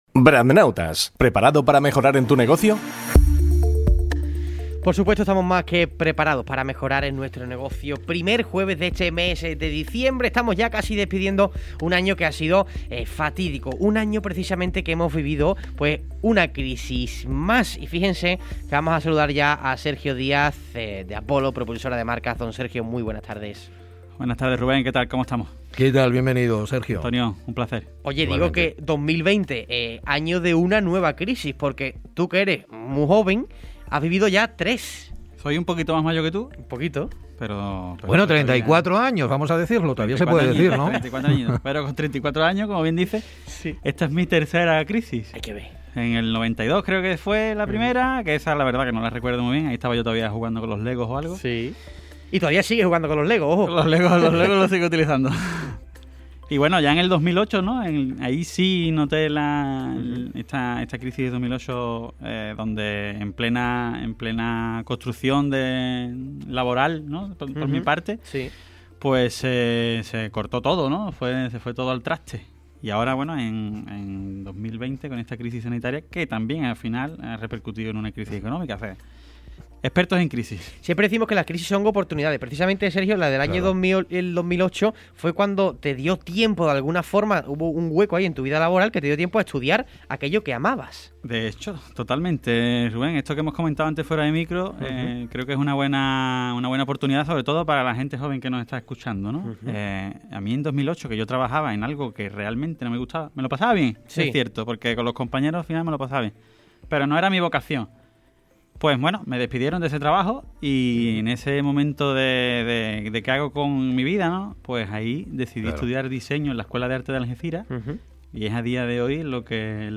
Programa de radio de Apolo.